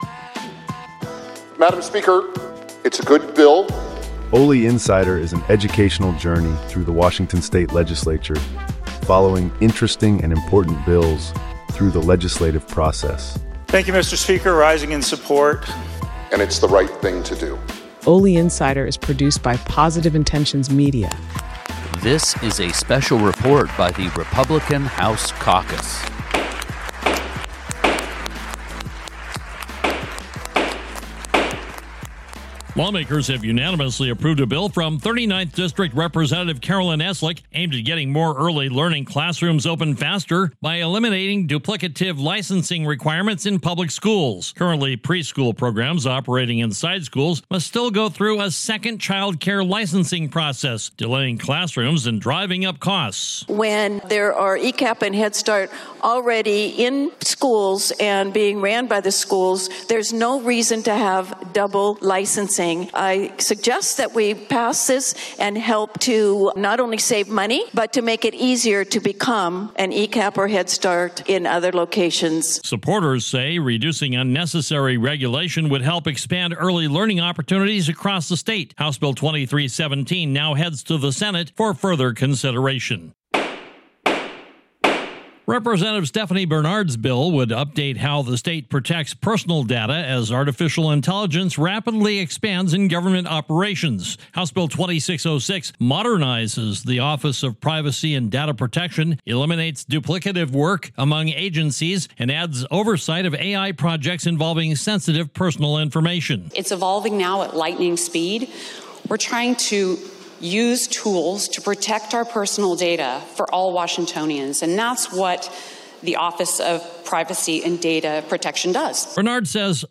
releases reports on bills with clips of representatives.